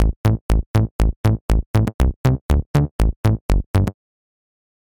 Накрутил бас арпеджио в Sylenth1, выставил синхронизацию темпа. Но какого-то ляда некоторые ноты успевают захватить на долю секунды следующую в арпеджиаторе, хотя ноты в миди-редакторе равной длины. Прилагаю проект и mp3, после, например, 8 ноты слышно, как "закусывает" на мгновение следующую ноту на октаву выше.